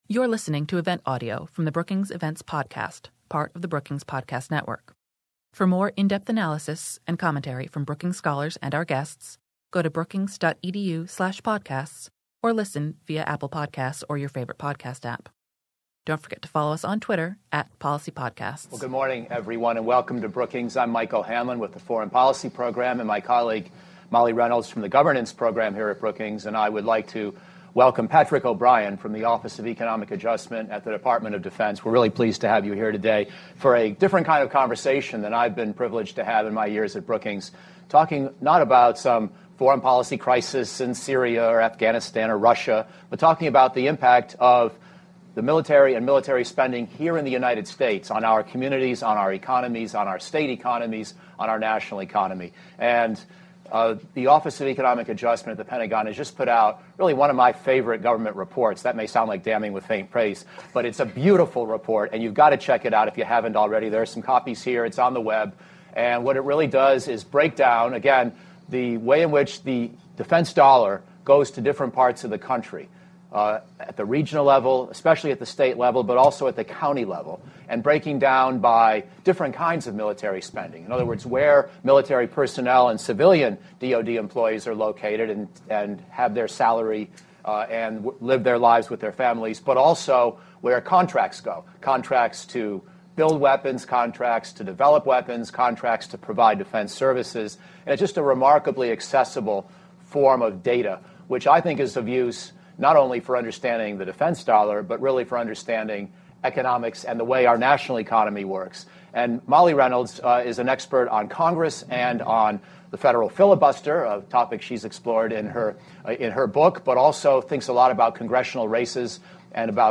hosted a discussion on the latest report